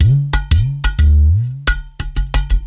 Udu_15(90BPM).wav